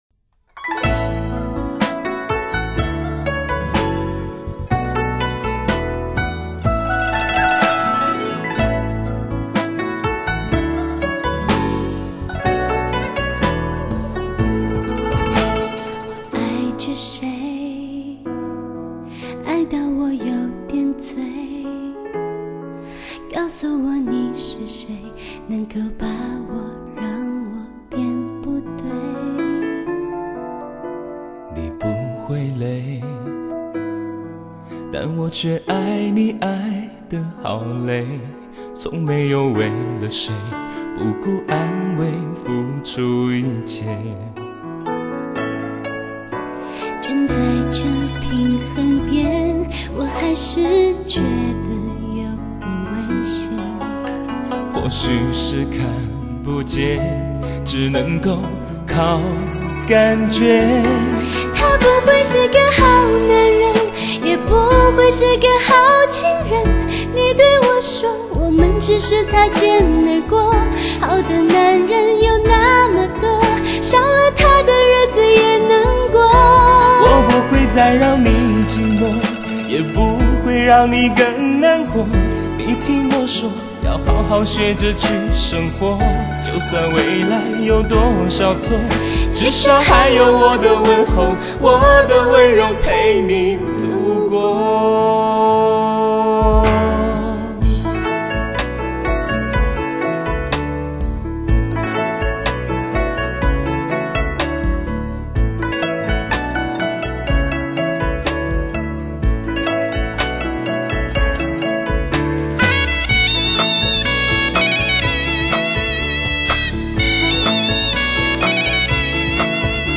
歌手用他低醇的嗓音，如情人般呢喃着有关爱痛情伤的故事，也只有这样温柔却哀伤的歌声才能触碰到我们最柔软的心弦。